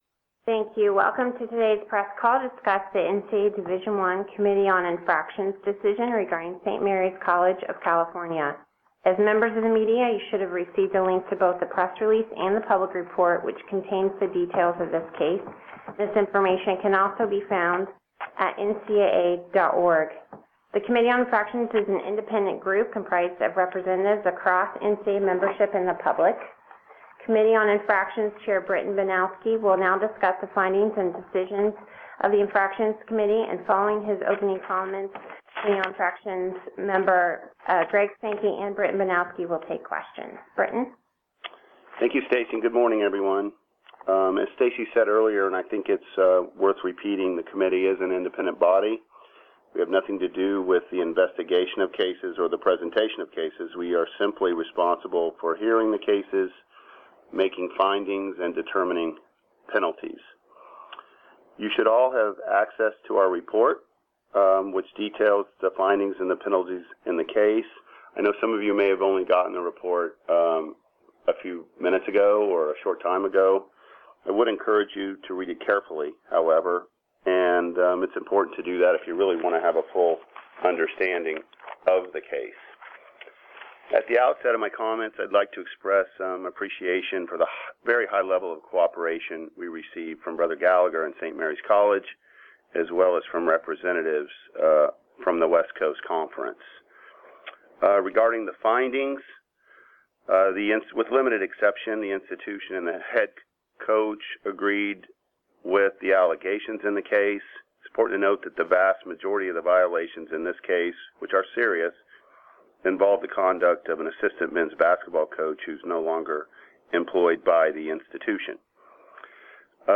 Telephonic press conference to discuss the NCAA Division I Committee on Infractions' decision regarding St. Mary's College of California